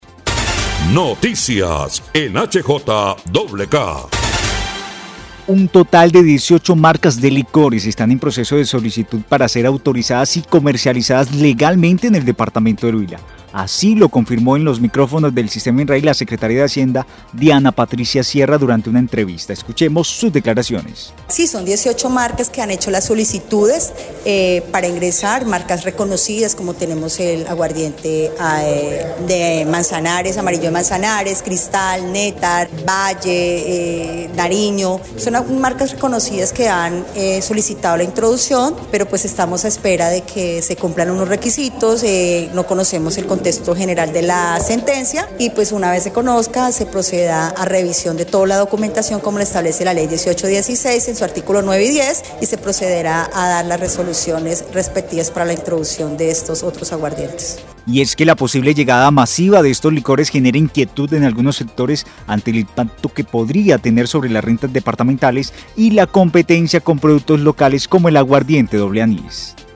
La Secretaria de Hacienda del Huila, Diana Patricia Sierra, confirmó en entrevista con el Sistema INRAI que reconocidas marcas como Amarillo de Manzanares, Cristal, Néctar, Valle y Nariño ya presentaron su solicitud formal para entrar al mercado huilense.